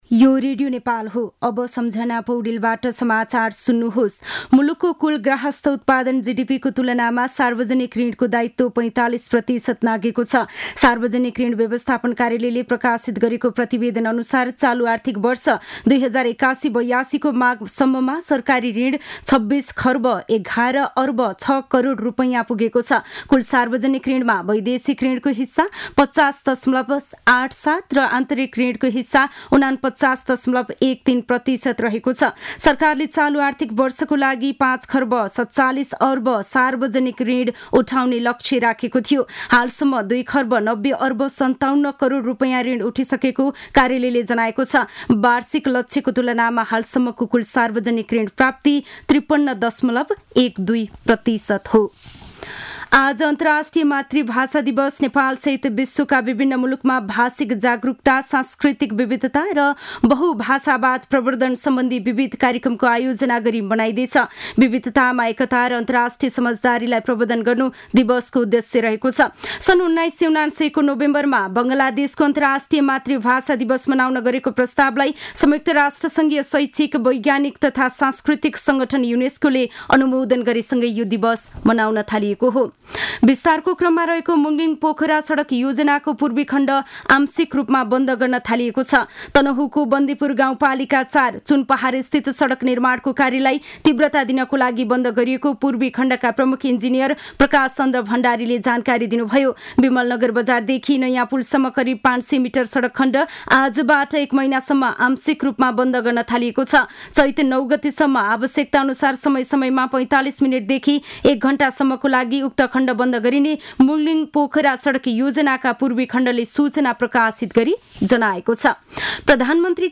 मध्यान्ह १२ बजेको नेपाली समाचार : १० फागुन , २०८१